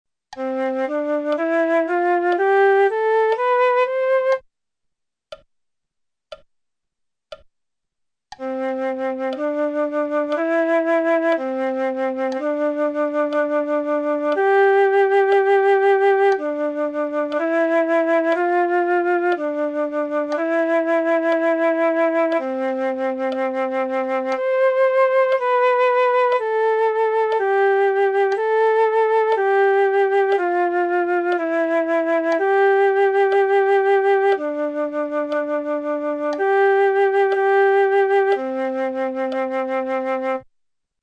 Prima dei dettati veri e propri, sentirai le note che verranno proposte, seguite da una battuta vuota scandita nella divisione dal metronomo.
Note: Do - Re - Mi - Fa - Sol - La - Si - Do
Tempo: 4/4